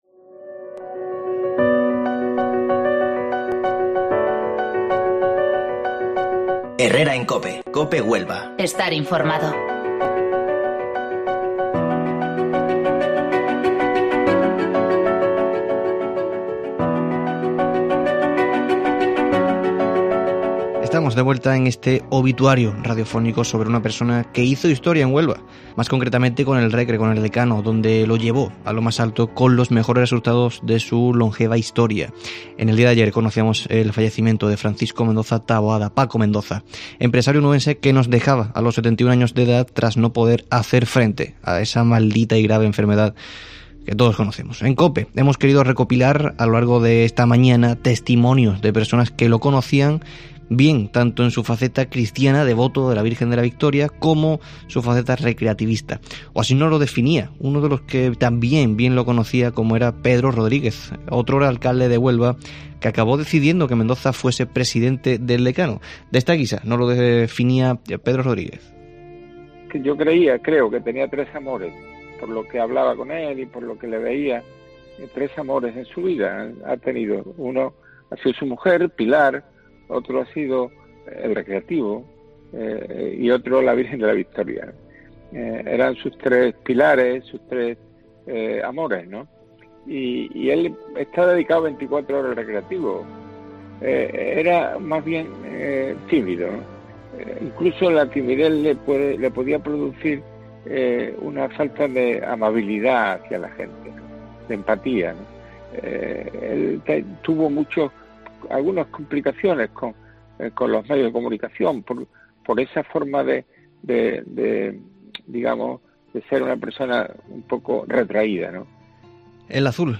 con testimonios de amigos